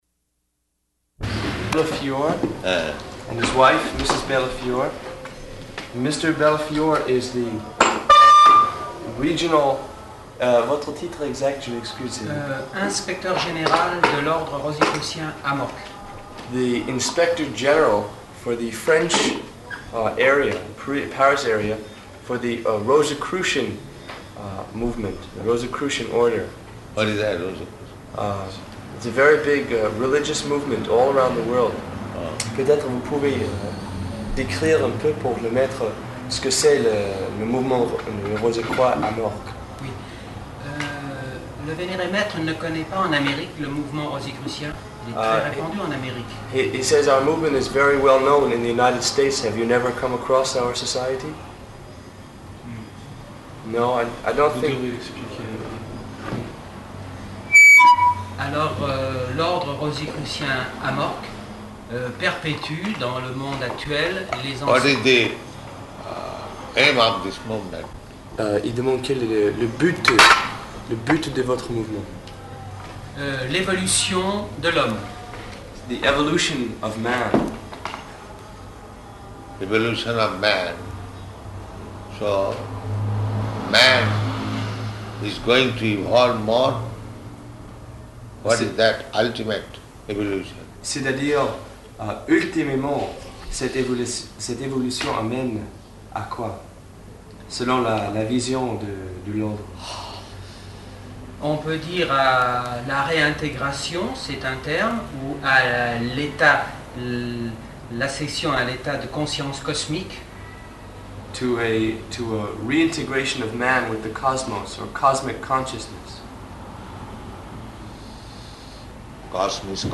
Type: Conversation
Location: Paris